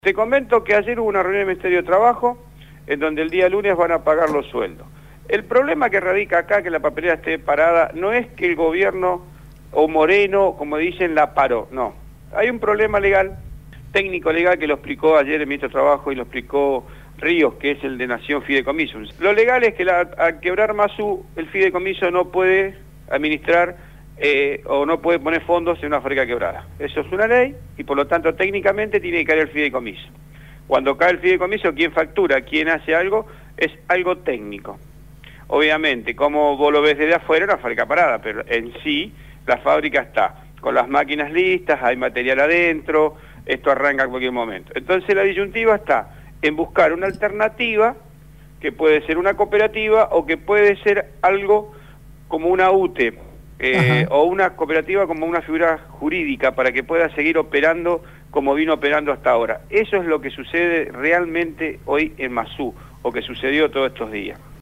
Los compañeros de «Patas Cortas» entrevistaron